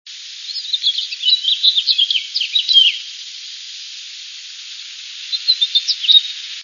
finch_house310A.wav